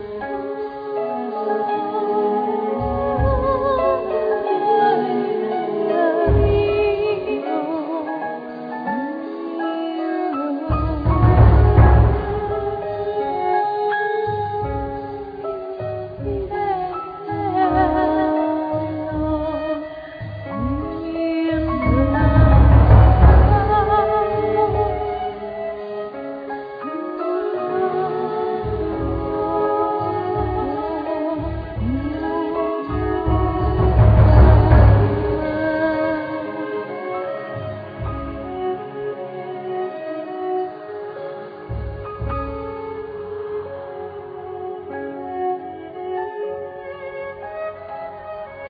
Keyboards, sing, percussions, flutes
Percussions,narration
Violin